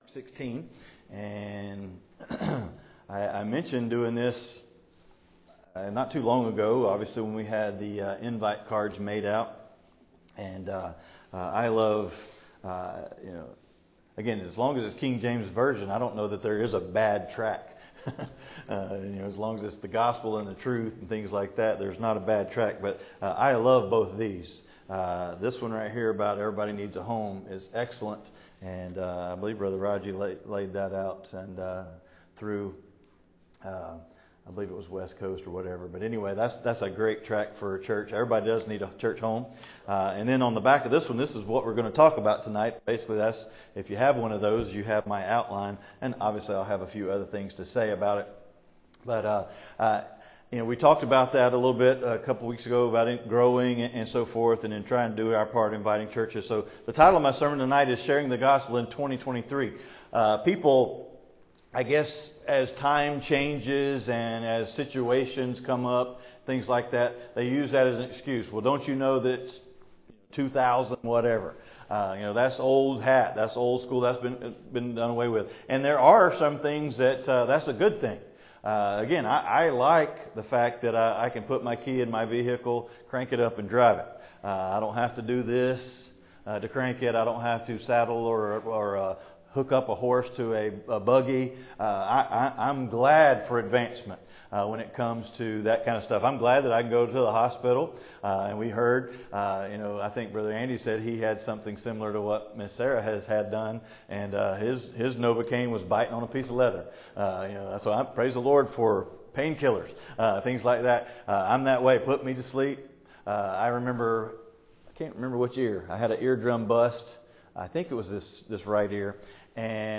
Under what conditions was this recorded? Mark 16:15 Service Type: Wednesday Night « Know as Much as Possible About Your Opponent